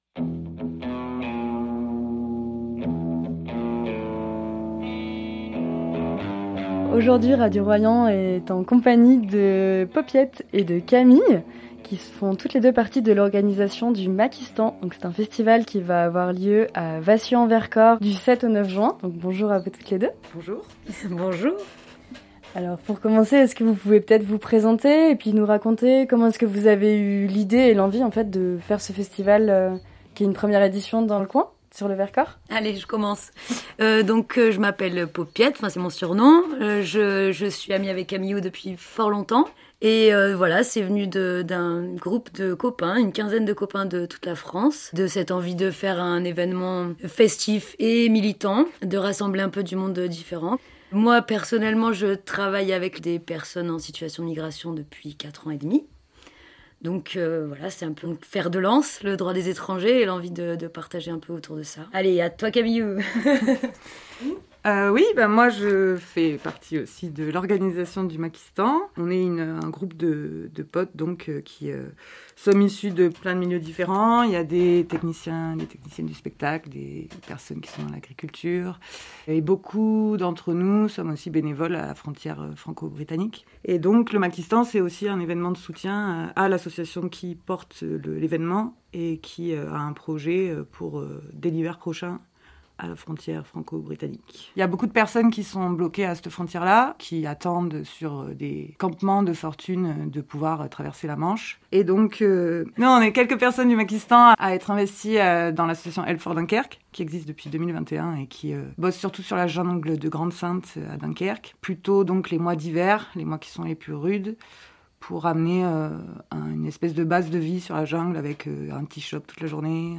Elles détaillent les thématiques abordées et la programmation du festival au micro de Radio Royans Vercors. Elles reviennent notamment sur l’origine du projet, organisé par l’association Strix qui vient en soutien aux personnes en situation d’exil sur les côtes Nord de la France.